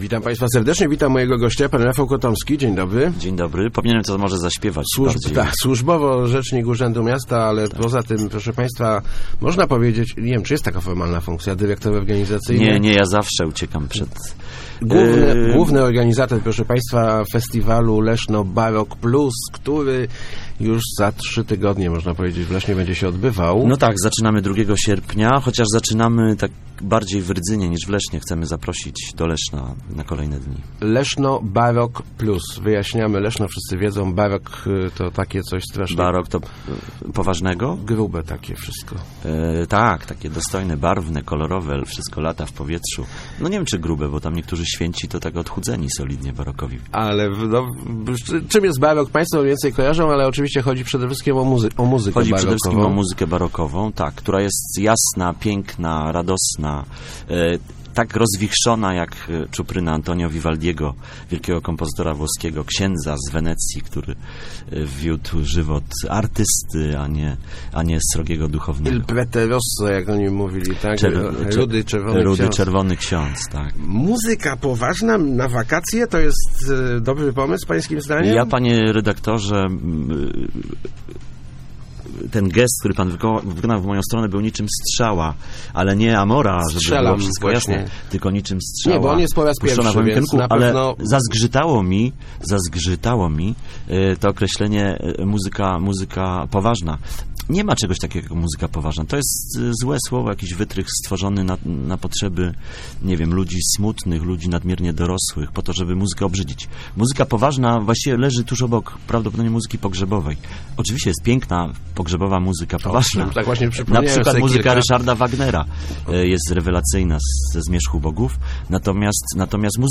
Rozmowach Elki